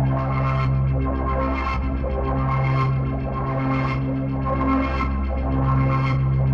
Index of /musicradar/dystopian-drone-samples/Tempo Loops/110bpm
DD_TempoDroneE_110-C.wav